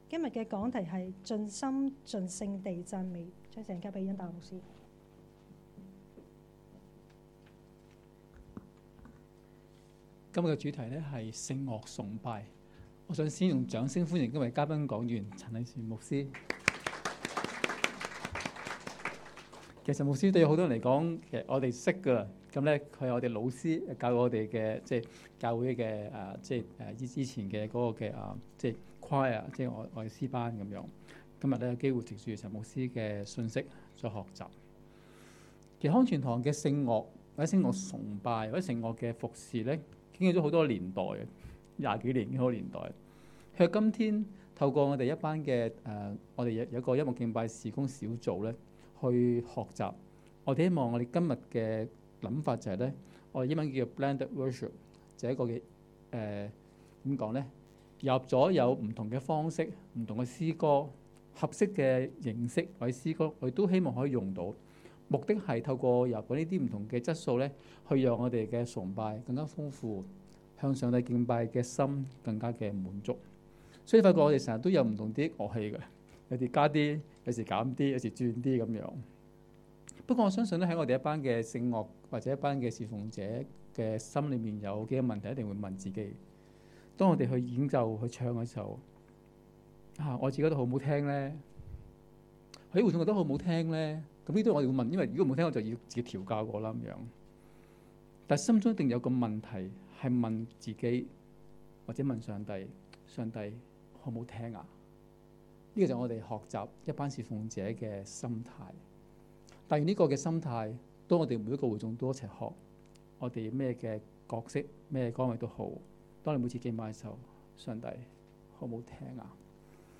2016年7月23日及24日崇拜
講道：盡心盡性地讚美